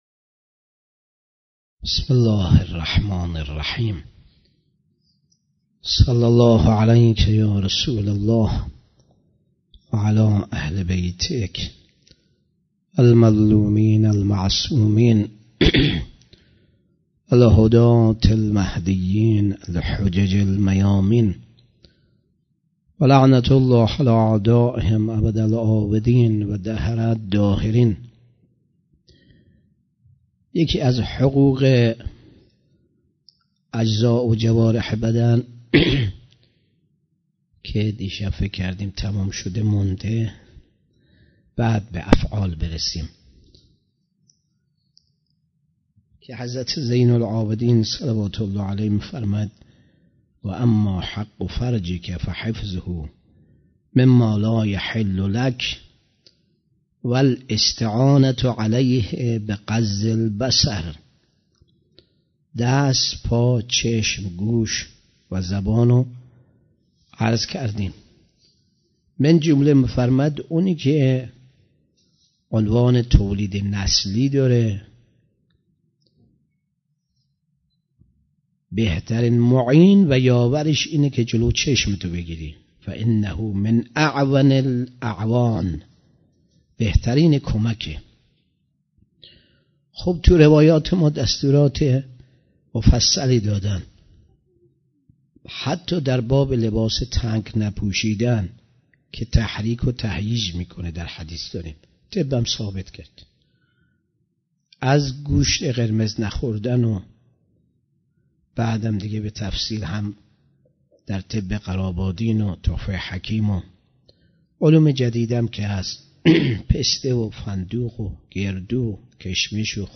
شب هفتم رمضان 96 - هیئت ام ابیها - سخنرانی